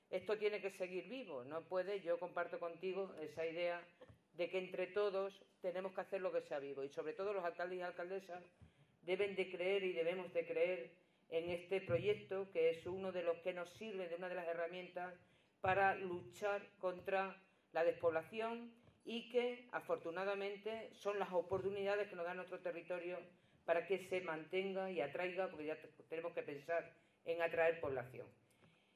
CORTES DE VOZ
02/05/2019, Castañar de Ibor.- La localidad de Castañar de Ibor ha sido el escenario de apertura de la X Semana Europea de Geoparques, con un acto en el que se han dado cita representantes políticos y agentes sociales de los municipios que conforman el Geoparque Mundial UNESCO Villuercas-Ibores-Jara, además de otros socios como la Junta de Extremadura, la Diputación de Cáceres o la Universidad de Extremadura, entre otros.